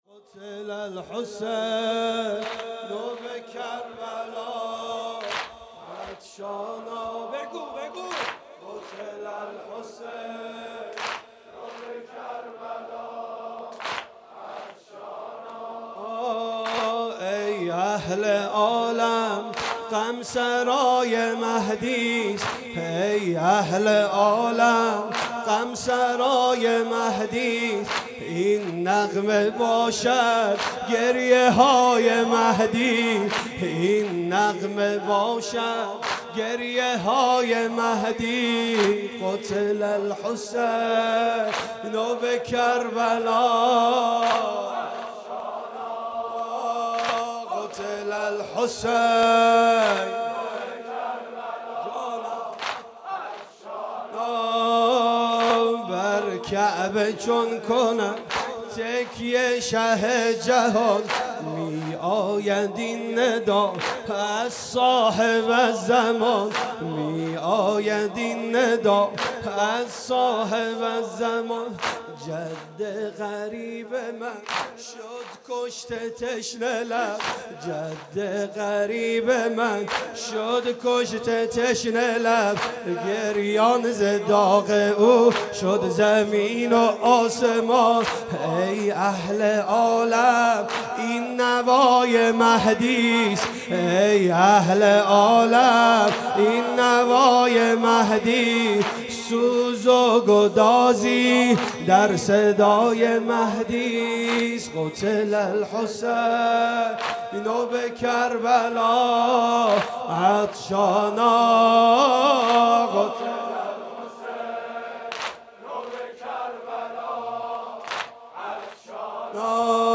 گلچين محرم 95 - واحد - قتل الحسین بکربلا